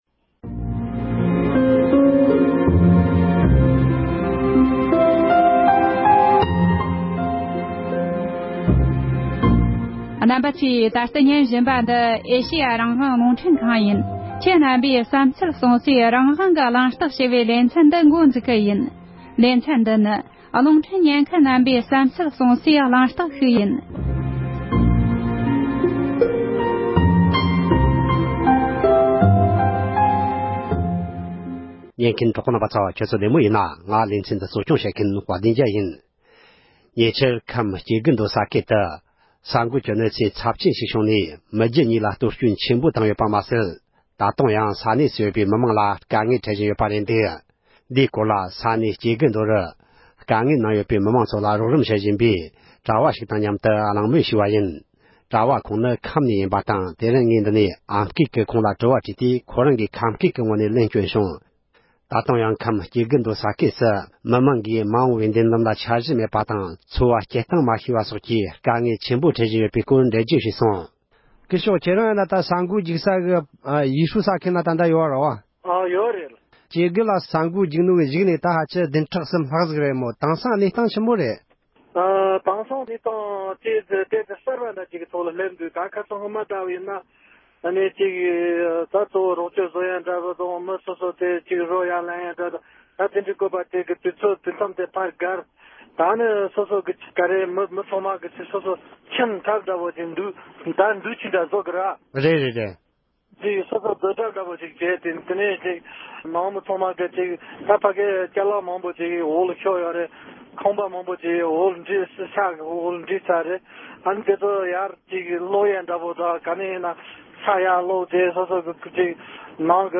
ཉེ་ཆར་ས་ཡོམ་གྱི་གོད་ཆགས་ཕོག་ཡུལ་བོད་ཁམས་སྐྱེ་རྒུ་མདོ་རུ་རྗེས་ཤུལ་བོད་མི་རྣམས་ཀྱི་གནས་སྟངས་སྐོར་གླེང་མོལ།